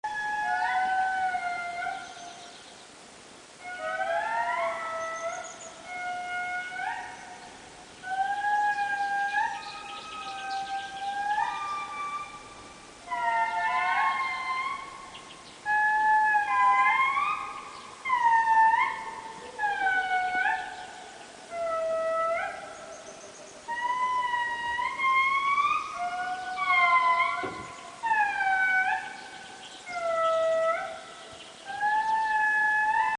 J’ai réalisé cet enregistrement  le matin de très bonne heure dans le parc de Anlamazaotra près d’Andasibe (Est de Madagascar)
sonindriindri.mp3